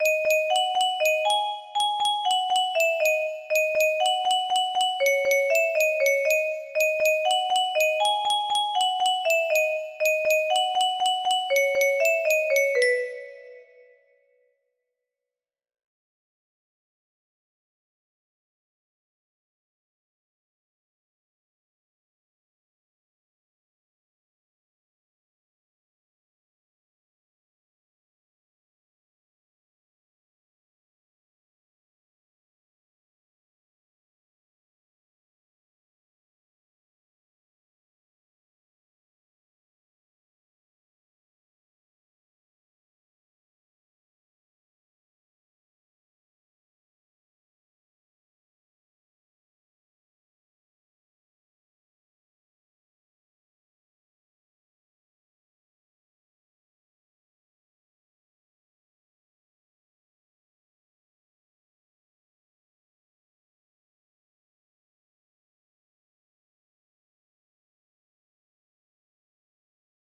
Unknown Artist - Untitled music box melody
Yay! It looks like this melody can be played offline on a 30 note paper strip music box!